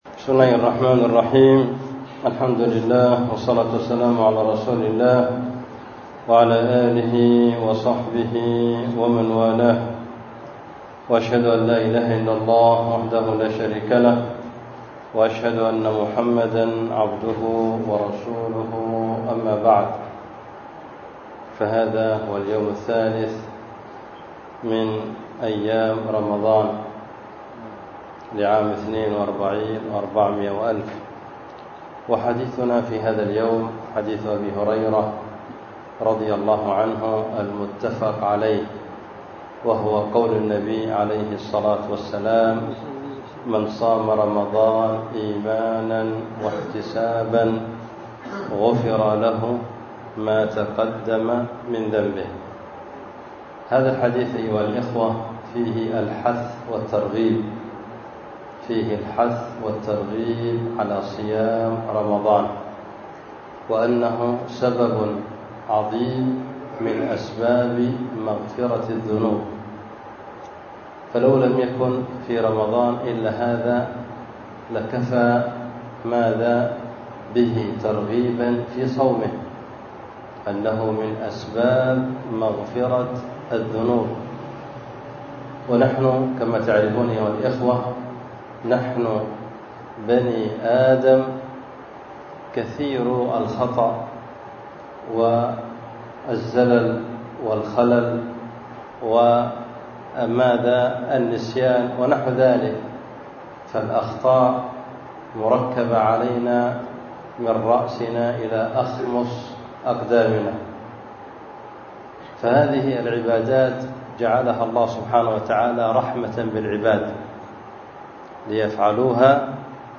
بدار الحديث في مسجد النصيحة بالحديدة